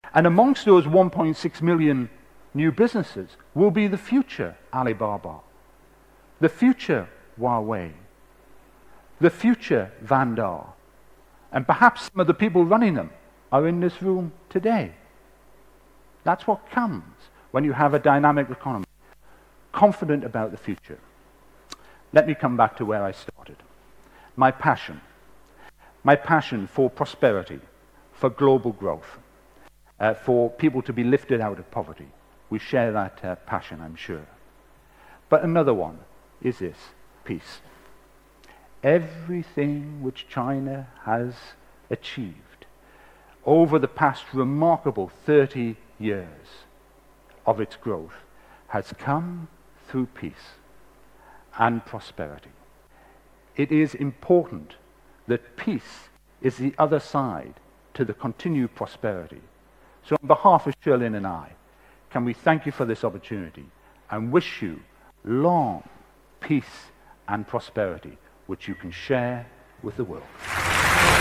TED演讲:我难忘的一次中国行(12) 听力文件下载—在线英语听力室